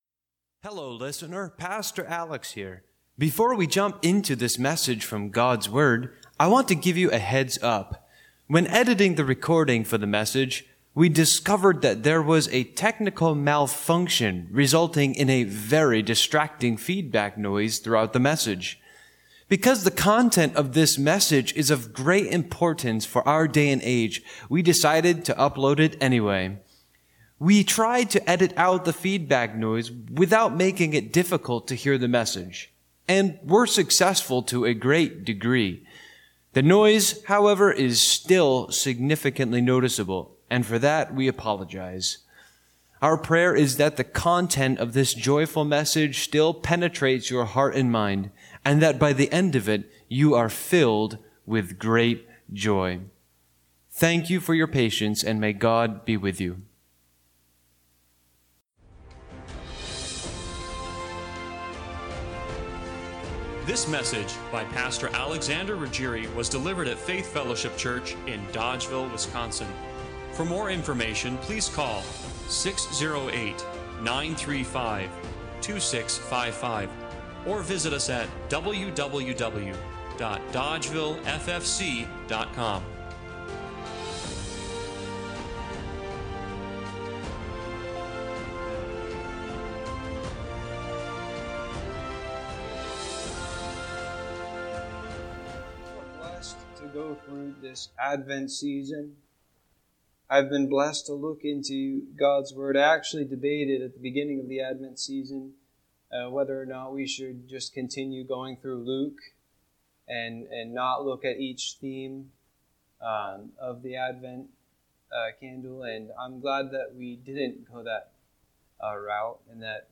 **TECHNICAL MALFUNCTION…MESSAGE HAS SIGNIFICANT FEEDBACK NOISE** Statistics show that depression
Service Type: Sunday Morning Worship